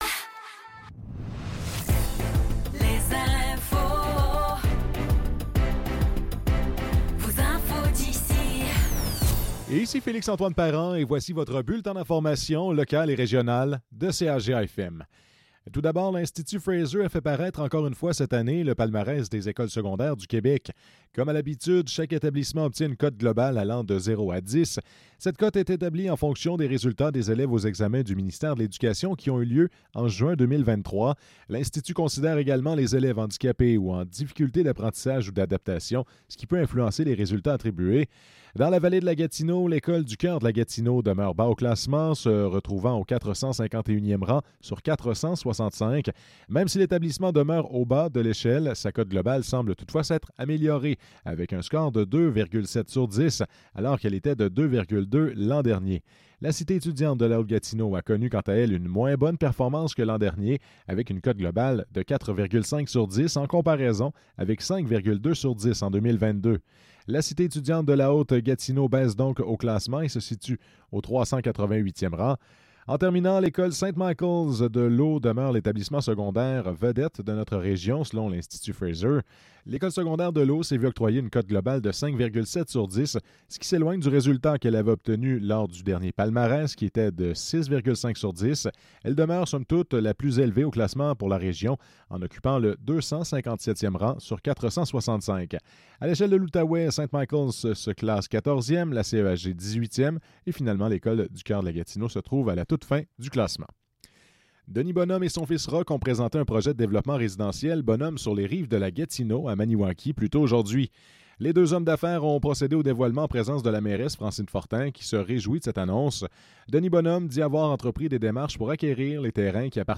Nouvelles locales - 18 novembre 2024 - 15 h